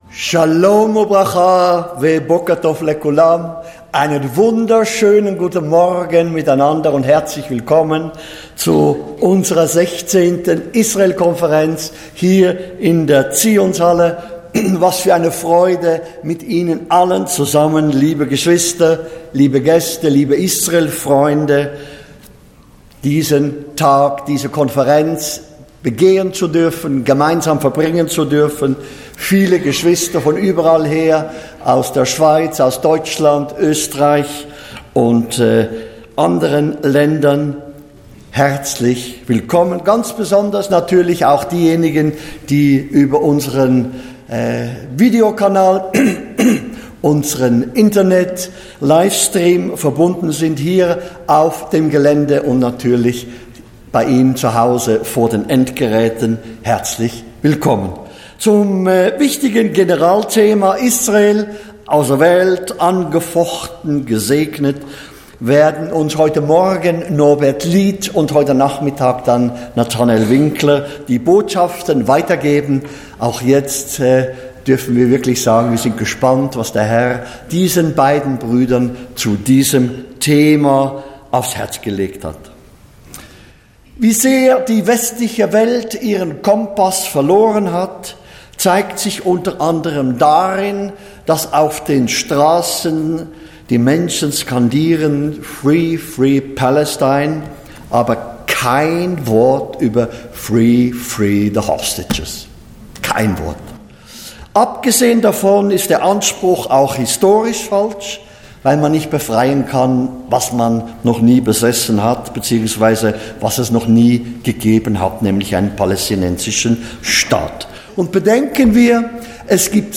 Einleitungen Gottesdienst https